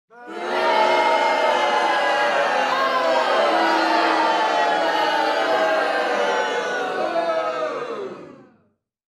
Shouting Crowd Sound Effect Free Download
Shouting Crowd